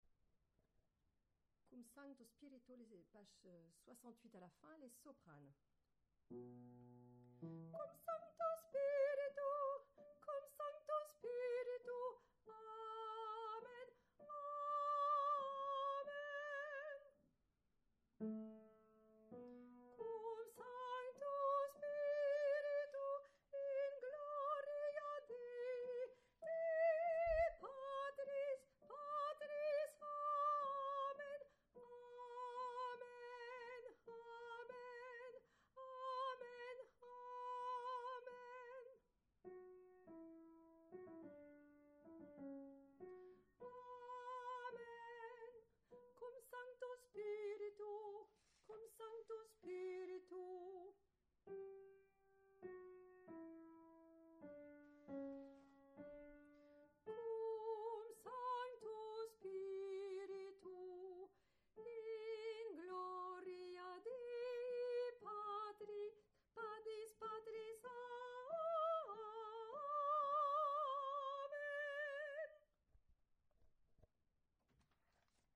cumsancto2_Soprano.mp3